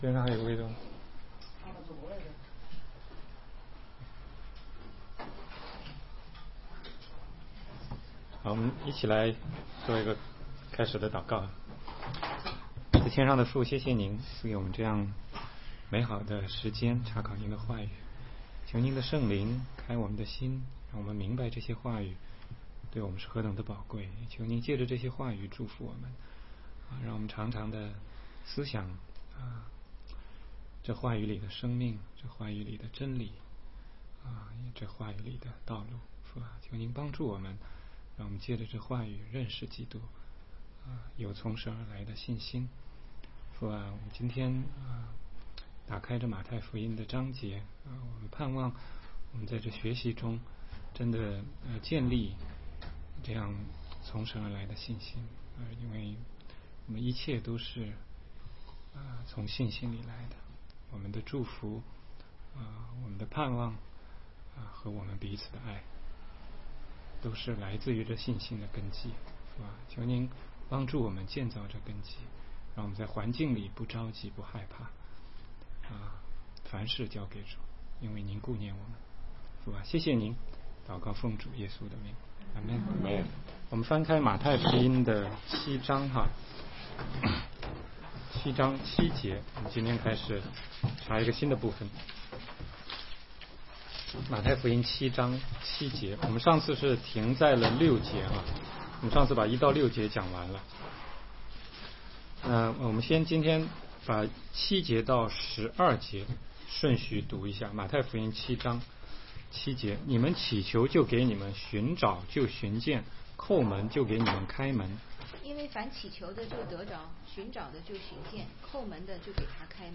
16街讲道录音 - 马太福音7章7-14节